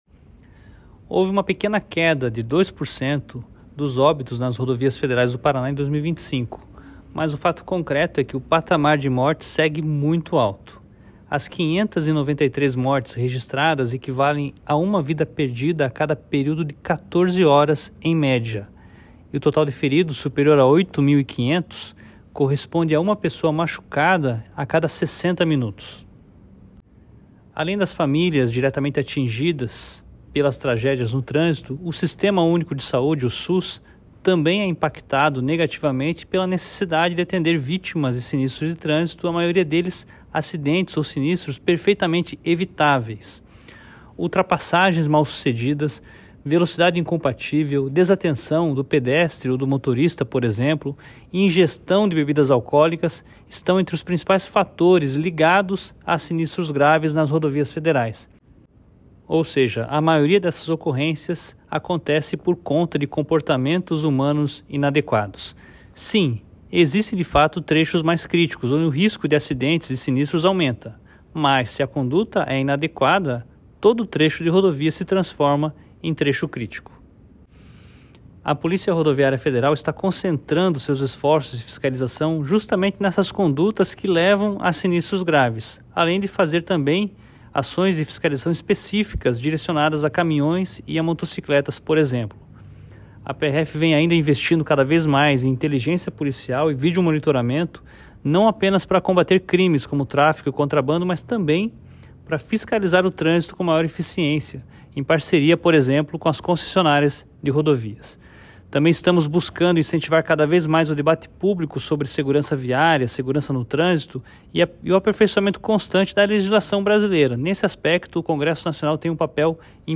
Acompanhe abaixo a fala de Fernando César Oliveira, superintendente da PRF no Paraná